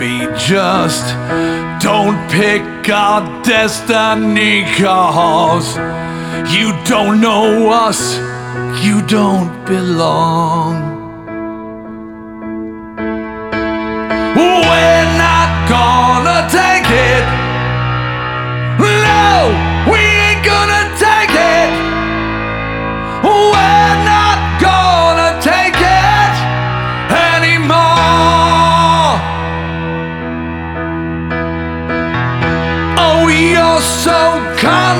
Жанр: Рок / Метал